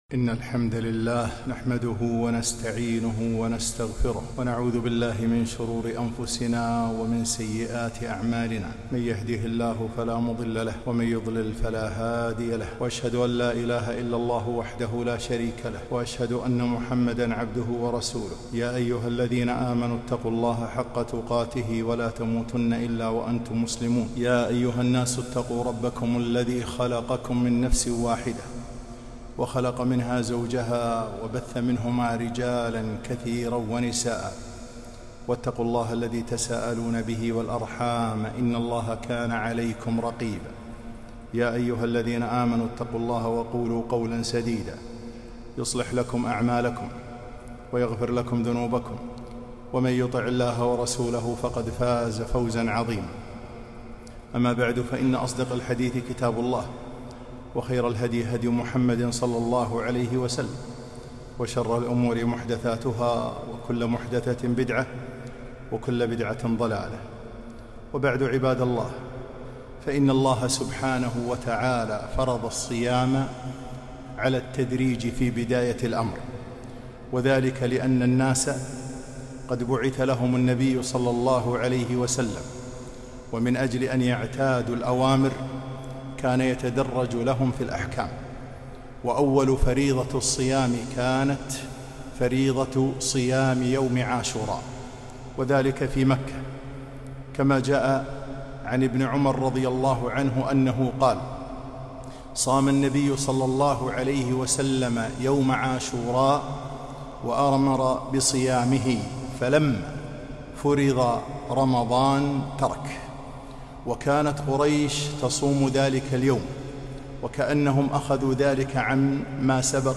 خطبة - وسائل مغفرة الذنوب